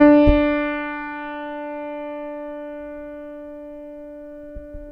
Le système d'analyse étant mis au point, on se propose de faire l'analyse d'un son enregistré, une note joué au piano.
Le signal analysé n'est pas périodique sur sa durée totale mais il l'est approximativement sur un intervalle de temps réduit.